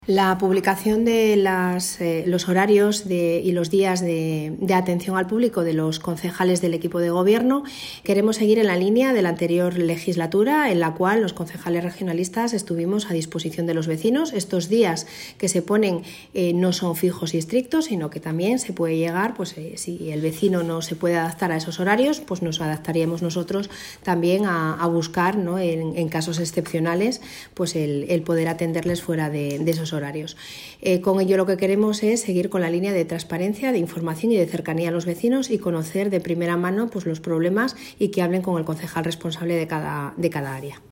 Alcaldesa-sobre-horarios-de-atencion-de-concejales-del-PRC.mp3